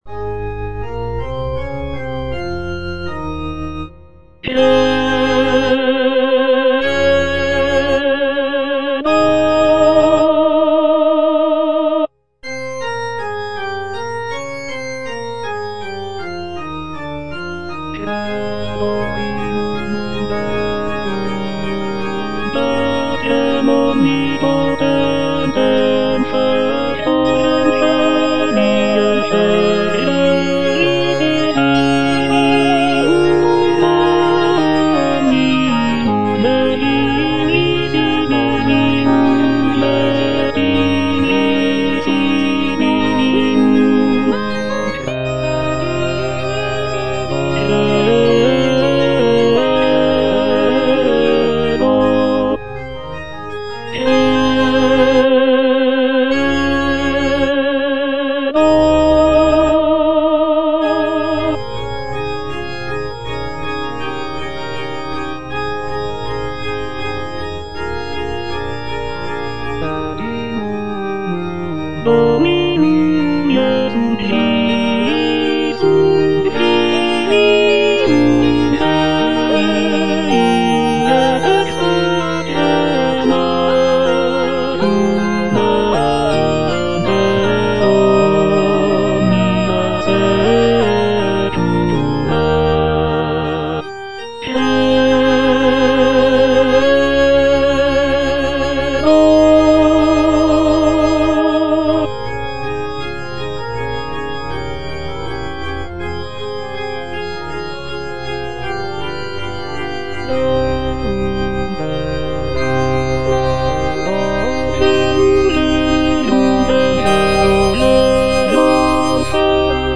Credo - Tenor (Emphasised voice and other voices) Ads stop